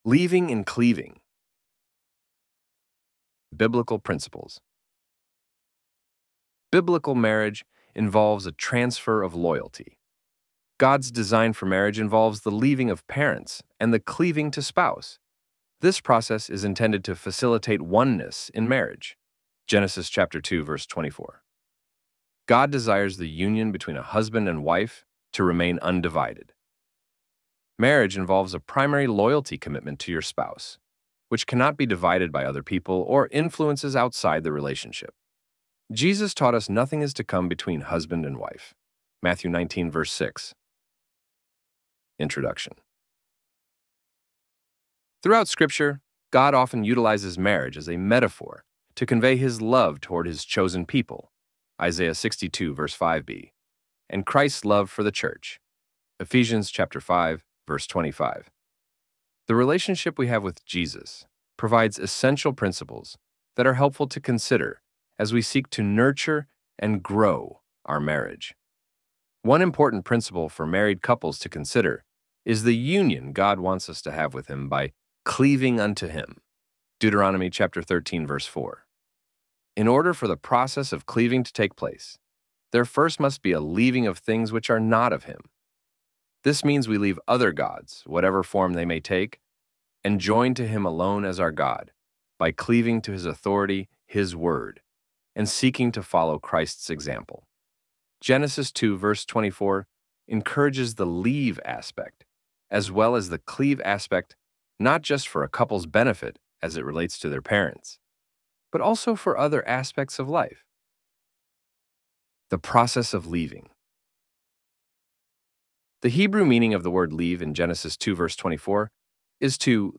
ElevenLabs_Leaving__Cleaving.mp3